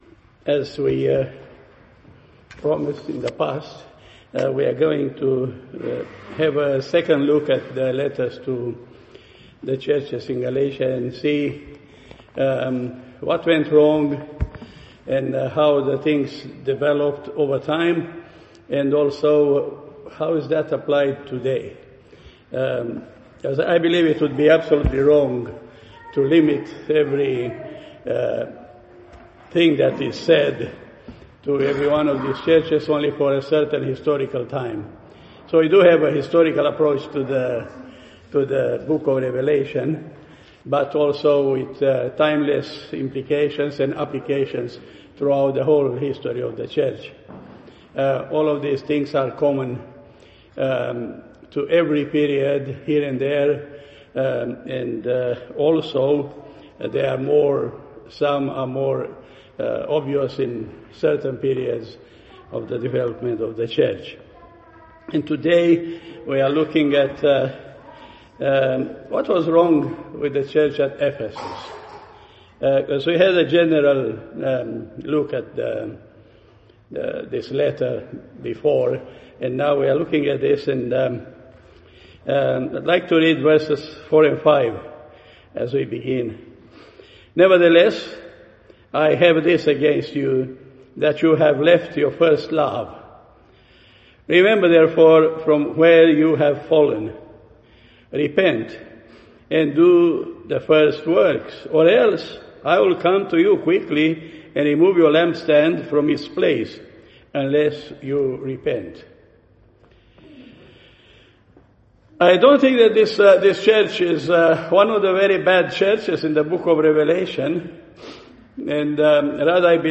Service Types Sunday Evening » Cornerstone Baptist Church